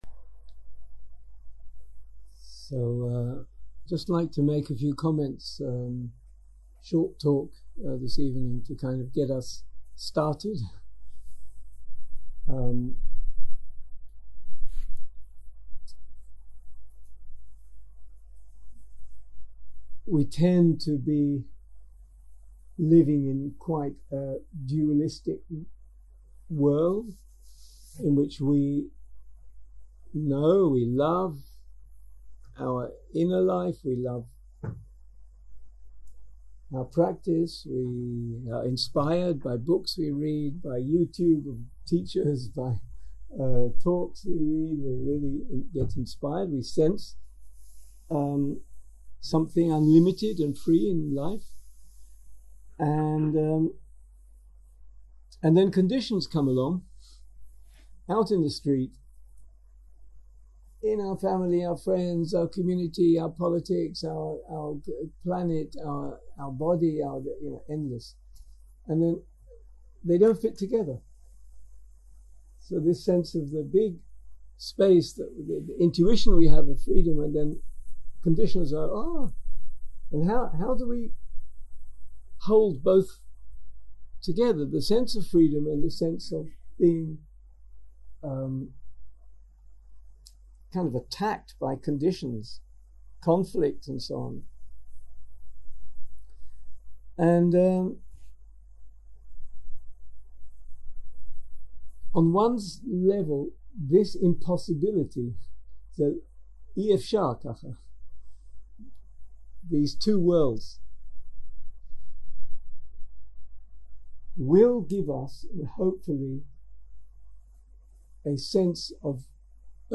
יום 1 - ערב - שיחת דהרמה - Me and the World Together - הקלטה 1 0:00 0:00 סוג ההקלטה: Dharma type: Dharma Talks שפת ההקלטה: Dharma talk language: English